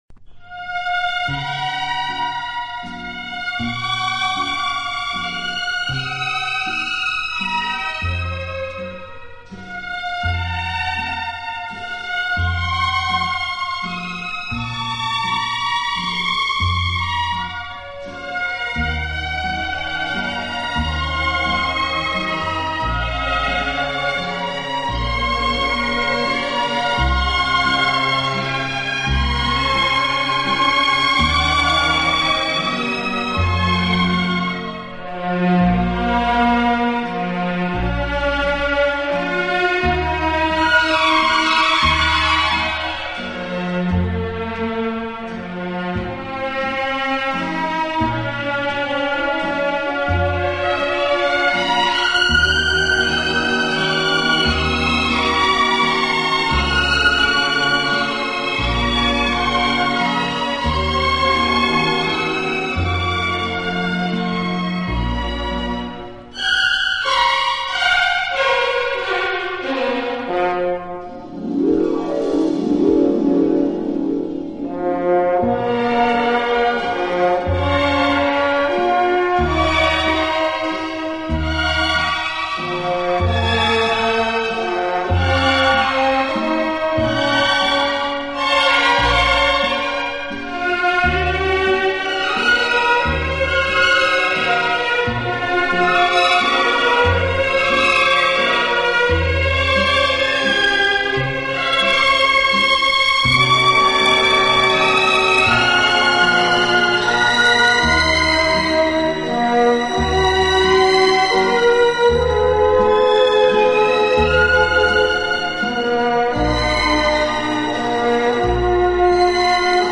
【轻音乐】
轻快、节奏鲜明突出，曲目以西方流行音乐为主。